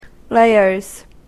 /ˈleɝz(米国英語), ˈleɪɜ:z(英国英語)/